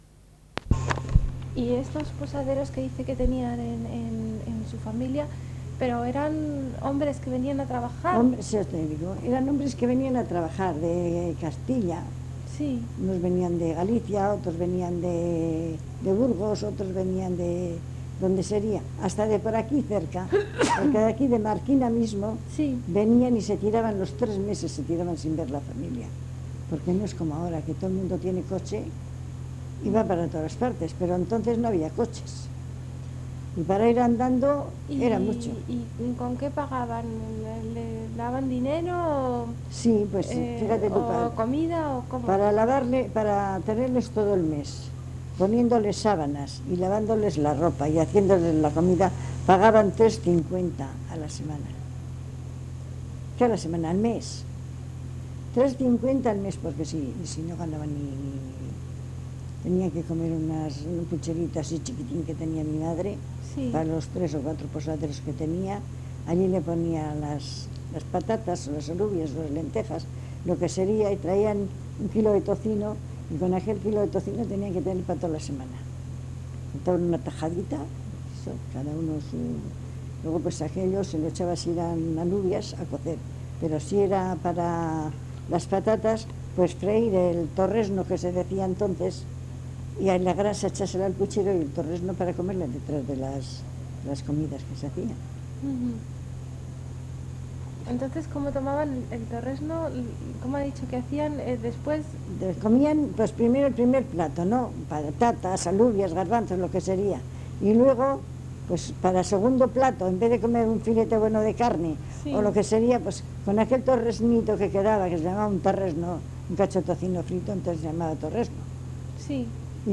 mujer